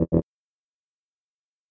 В коллекции представлены различные варианты предупреждающих сигналов, блокировок и системных оповещений.
Звук: дверь заблокирована